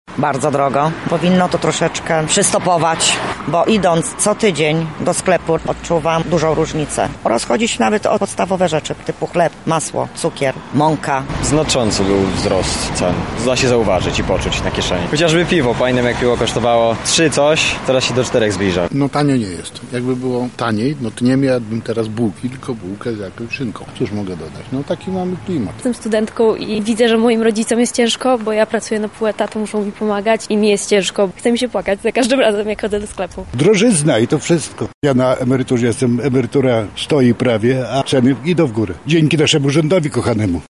[SONDA]: Jak radzimy sobie z drożyzną?
Zapytaliśmy mieszkańców o to, co myślą na temat obecnych cen.